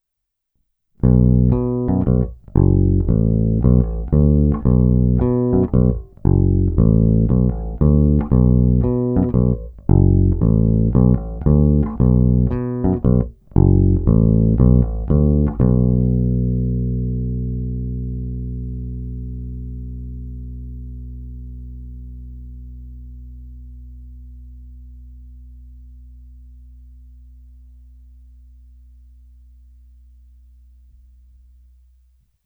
Zvuk je tučný, na jeho pozadí i přes tupé struny slyším zvonivé vrčení typické právě pro modely 1957-1959.
Není-li řečeno jinak, následující nahrávky jsou provedeny rovnou do zvukové karty a kromě normalizace ponechány bez úprav. Tónová clona vždy plně otevřená.
Hra mezi snímačem a kobylkou